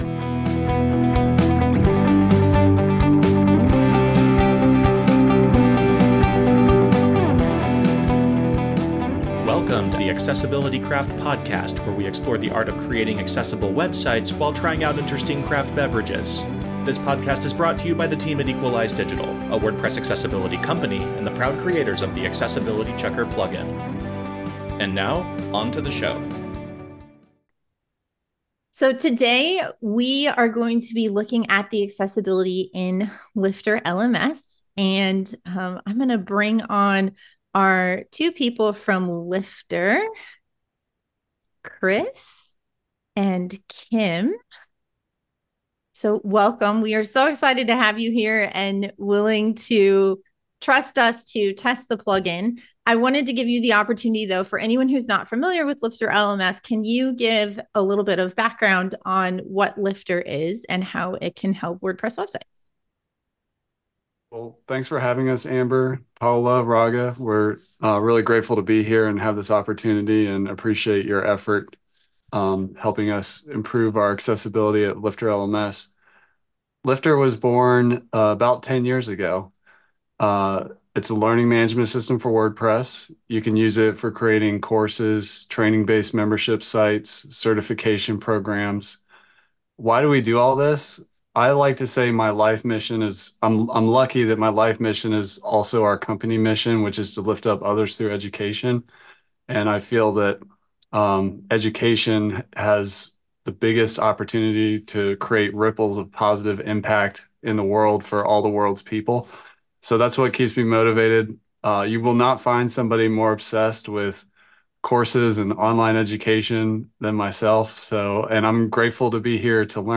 This episode is a recording of a June 2024 WordPress Accessibility Meetup